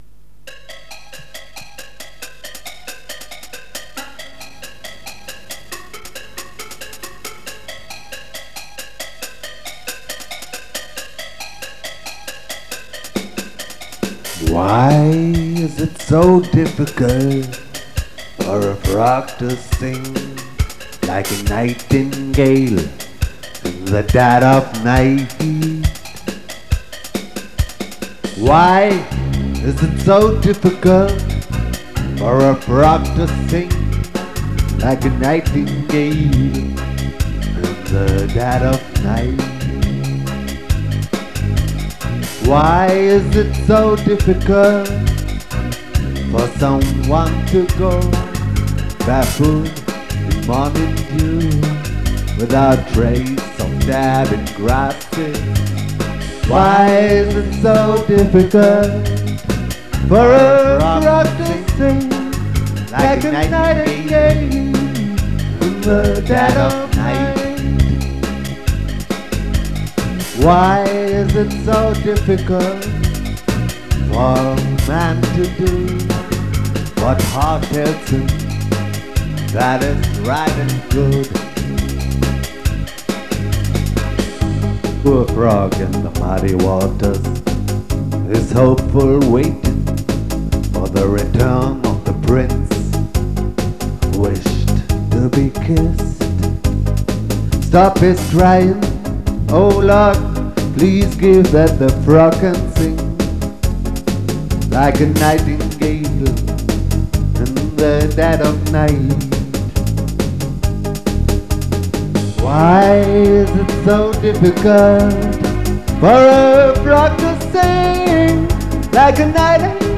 (Words, Music, Voice)